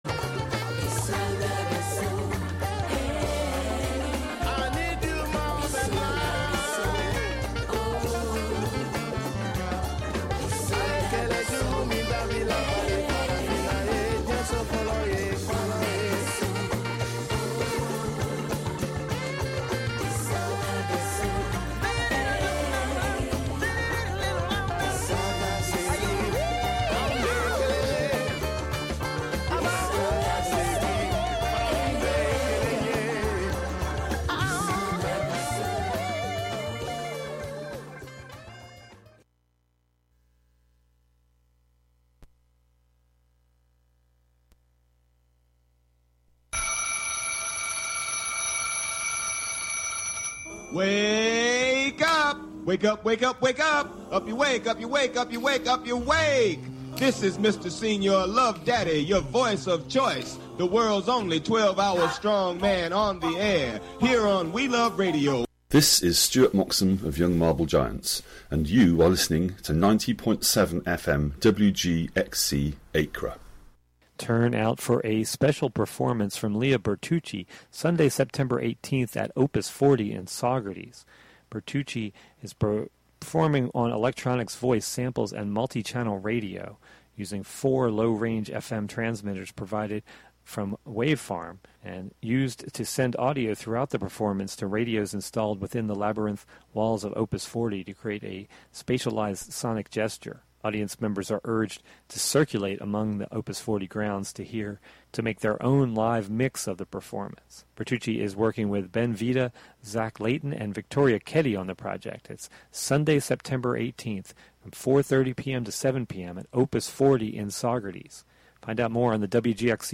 focusing on his piano music